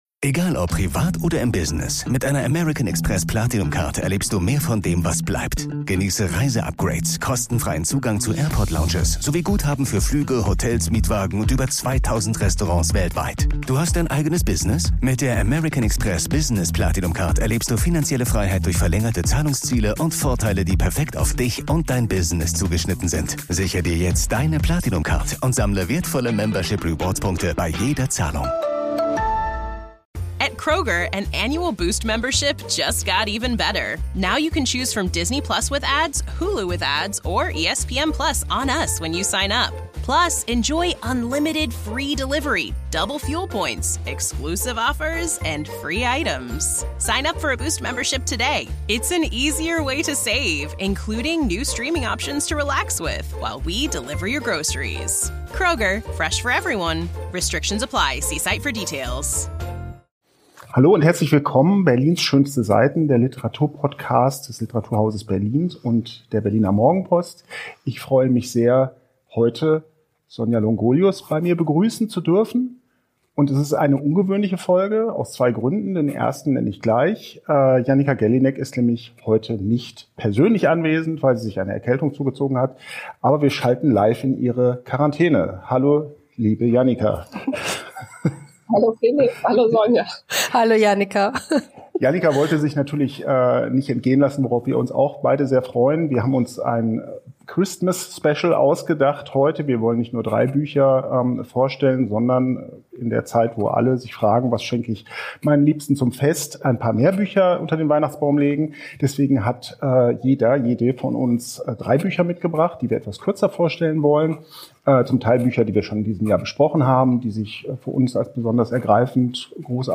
Drei Menschen, die sich für Literatur begeistern, treffen sich nun alle zwei Wochen, um sich darüber zu unterhalten, was sie derzeit im weiten...